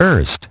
Транскрипция и произношение слова "erst" в британском и американском вариантах.